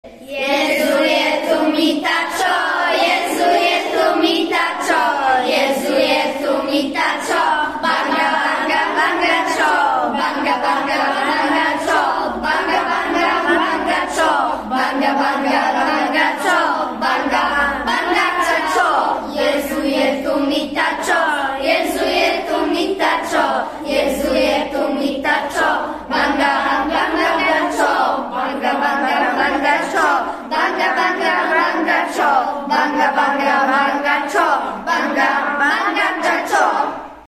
Wcześniej każda klasa przygotowała plakat o jednym z krajów z kontynentu, który jej został wyznaczony, a niektóre klasy nauczyły się śpiewać piosenki w języku w jakich mówi się na danym kontynencie czy też w kraju, o którym przygotowywały plakat.